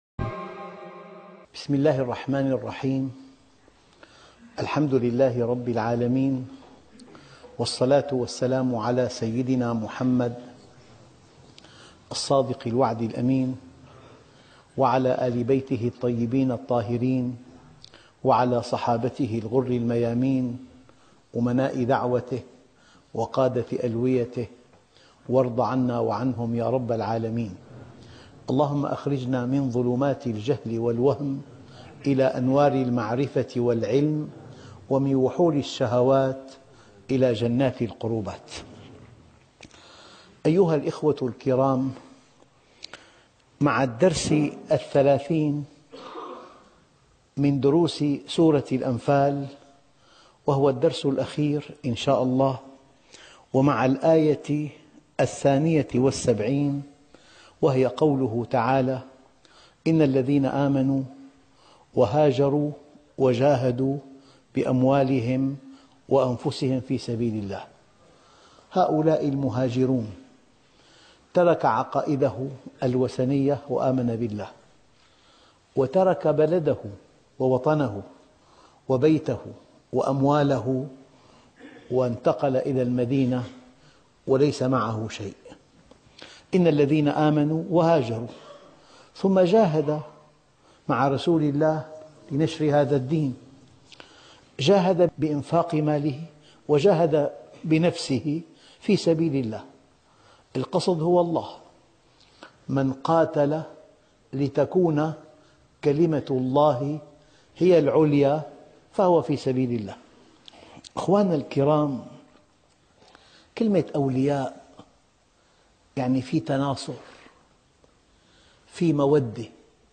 الدرس ( 30) تفسير سورة الأنفال - الشيخ محمد راتب النابلسي